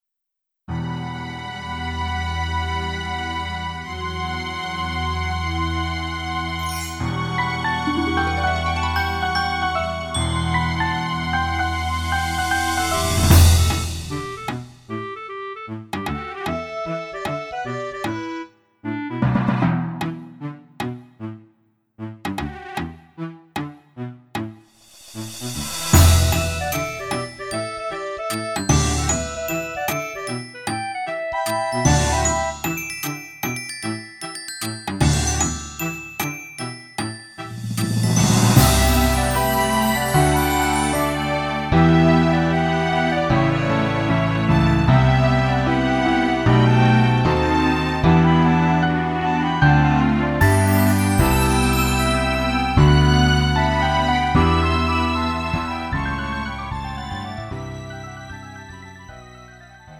음정 원키 3:37
장르 가요 구분